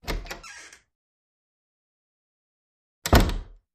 Creak
Door Open Close / Squeaks, Various; Wood Door Open Close 3